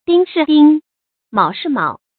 dīng shì dīng，mǎo shì mǎo
丁是丁，卯是卯发音